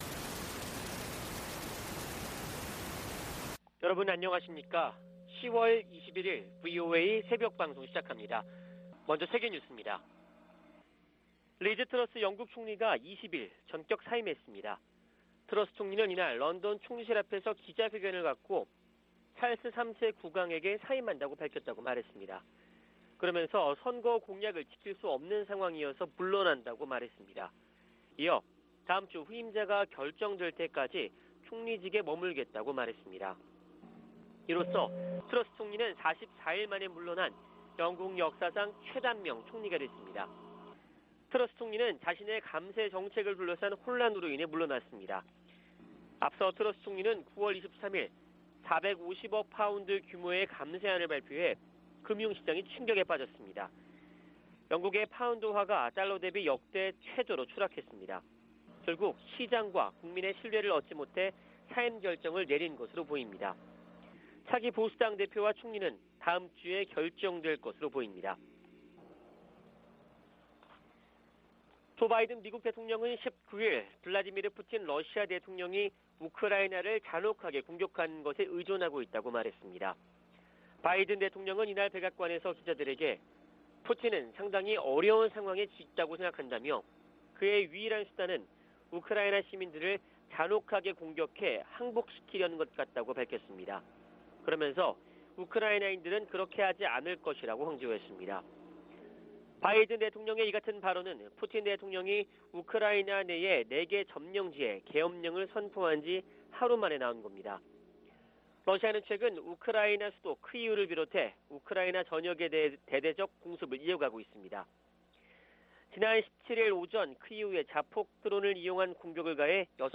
VOA 한국어 '출발 뉴스 쇼', 2022년 10월 21일 방송입니다. 미 국무부는 연이은 북한 포 사격에 심각한 우려를 나타내며 한국과 일본에 악영향을 줄 수 있다고 지적했습니다. 미 공군 전략폭격기 B-1B가 괌에 전개됐다고 태평양공군사령부가 확인했습니다. 한국의 다연장 로켓 구매 계약을 체결한 폴란드는 러시아의 침공을 저지하기 위해 이 로켓이 필요하다고 설명했습니다.